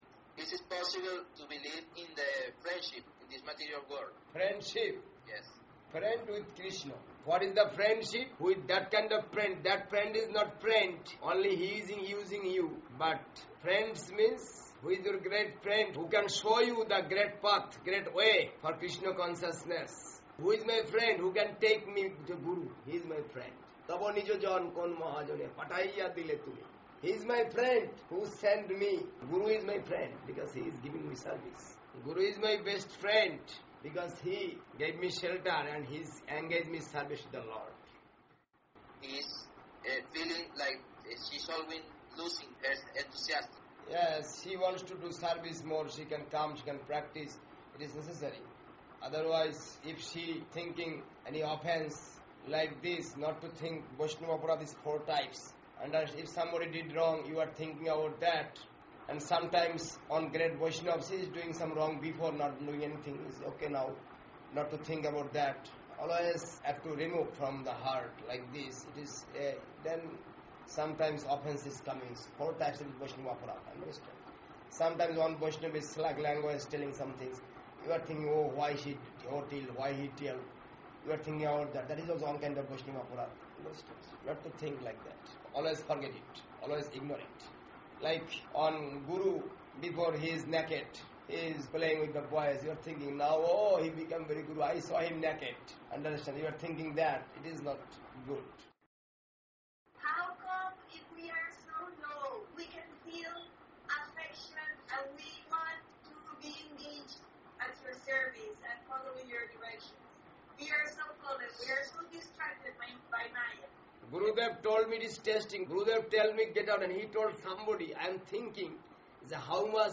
Speaking online to Venezuela & Colombia
Caracas Deity installation anniversary,